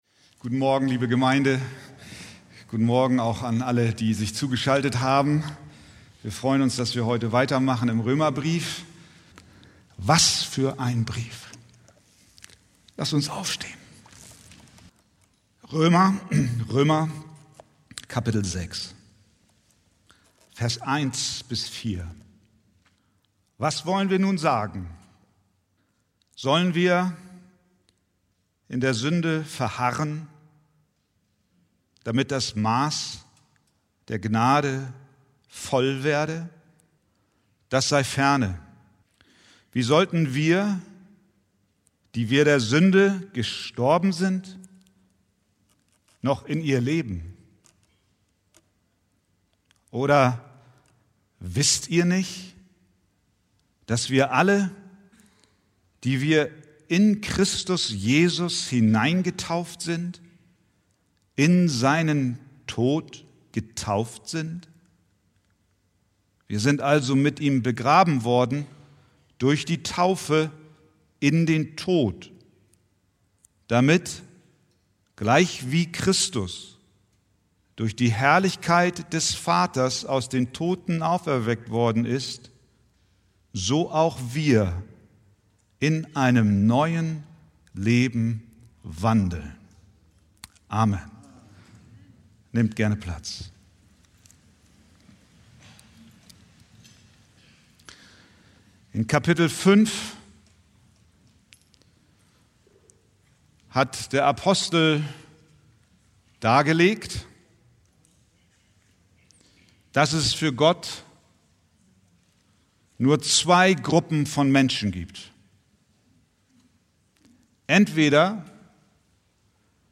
Predigttext: Röm. 6,1-4